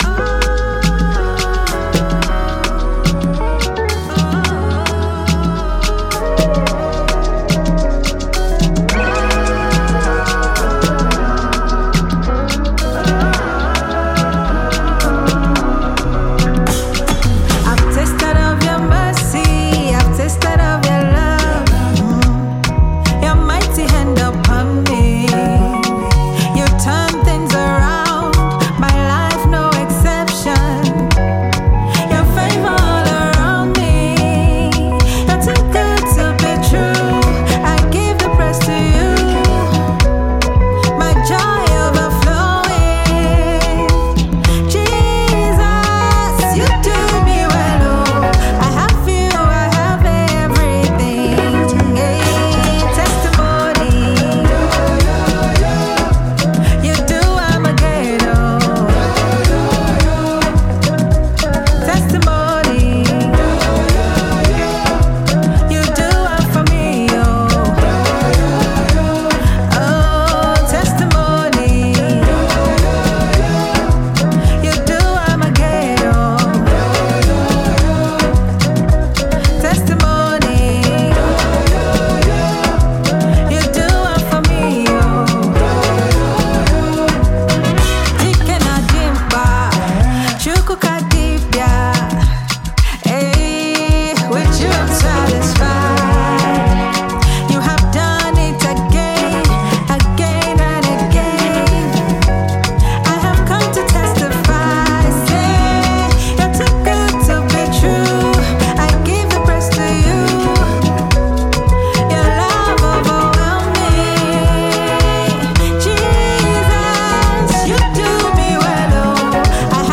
Nigerian gospel singer and songwriter
You will never forget the amazing melody of this song.